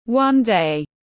Một ngày nào đó tiếng anh gọi là one day, phiên âm tiếng anh đọc là /wʌn deɪ/